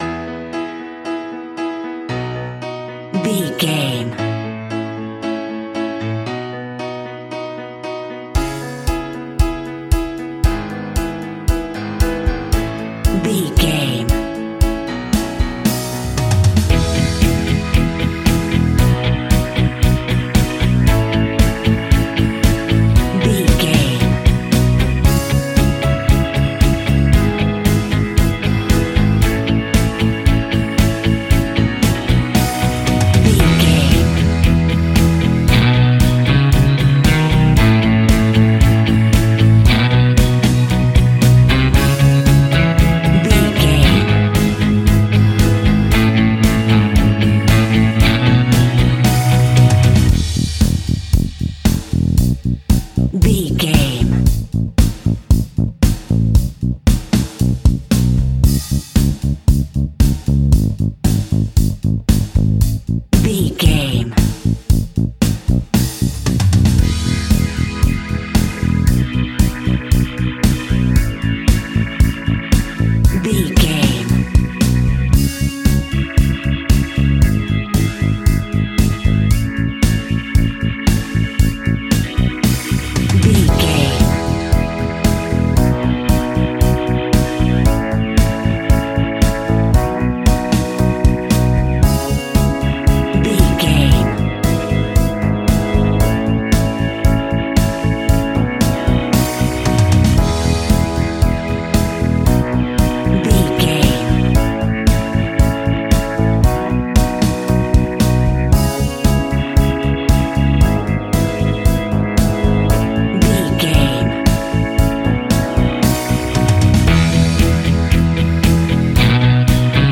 Epic / Action
Fast paced
Ionian/Major
pop rock
indie pop
fun
energetic
uplifting
acoustic guitars
drums
bass guitar
electric guitar
piano
organ